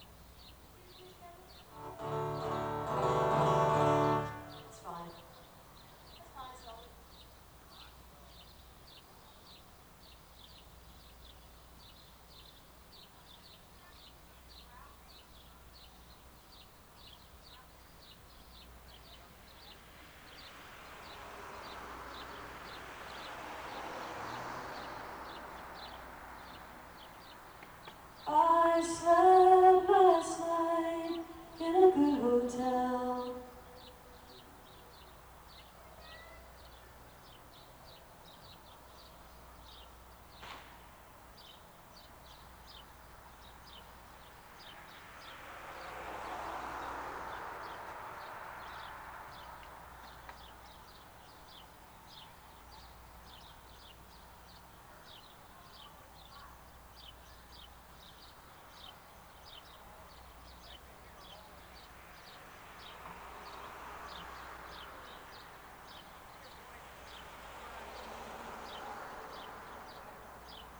(soundcheck)